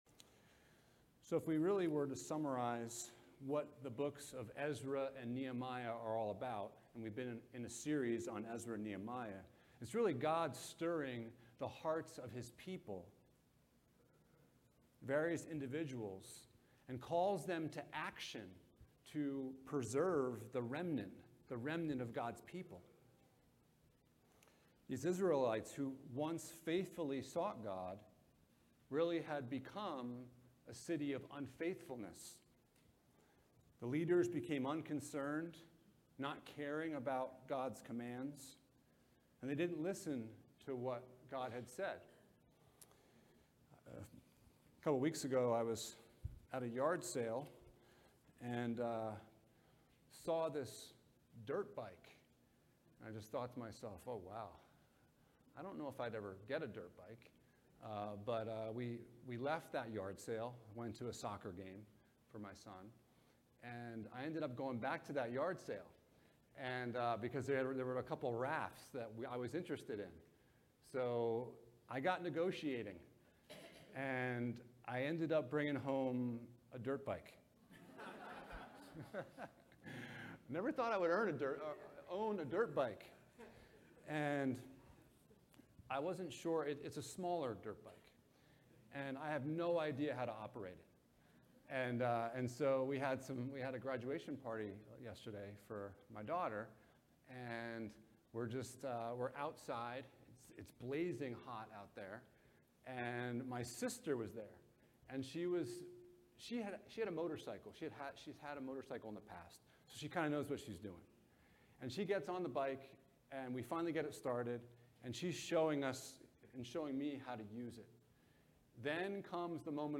Renovation of the Heart Passage: Nehemiah 9:38 - 10:39 Service Type: Sunday Morning « What is Your Worldview?